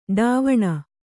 ♪ ḍāvaṇa